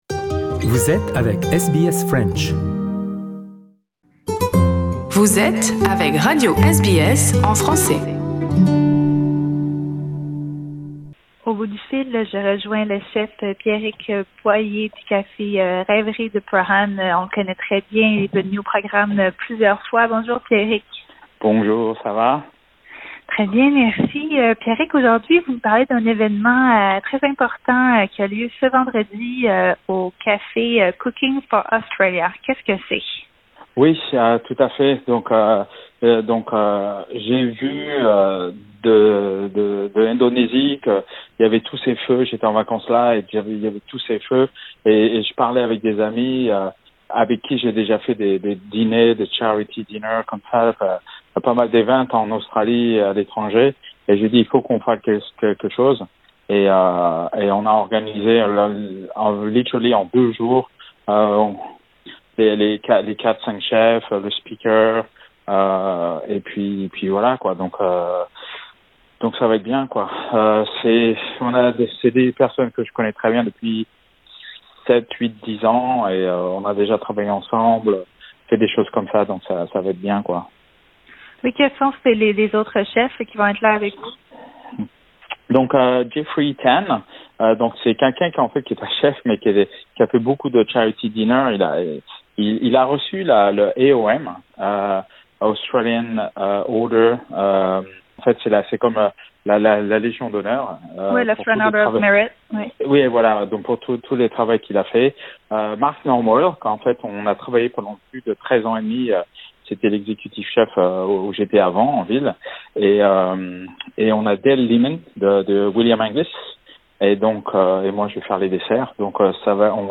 Ecoutez notre interview avec le chef français pour tous les détails.